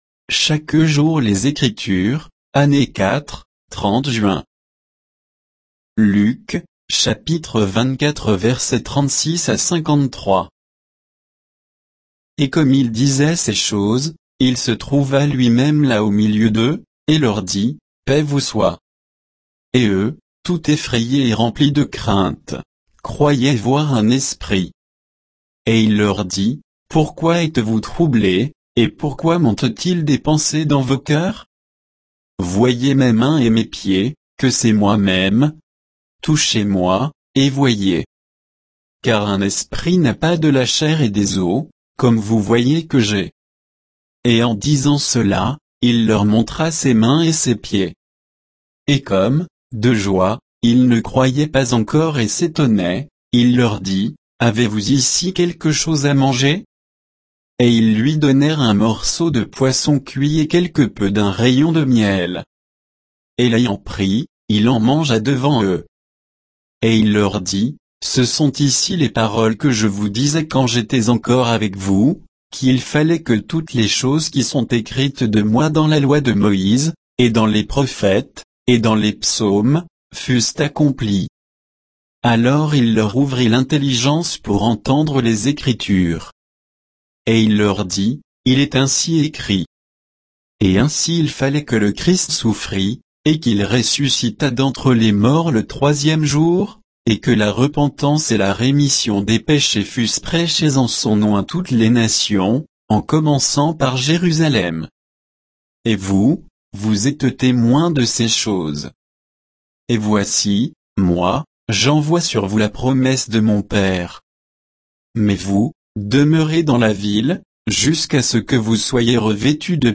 Méditation quoditienne de Chaque jour les Écritures sur Luc 24